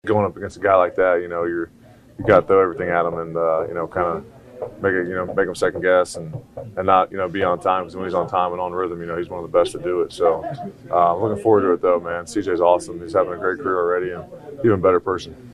Rookie edge rusher Jack Sawyer was a teammate of Texans’ quarterback CJ Stroud at Ohio State, and he says Stroud is driven to win.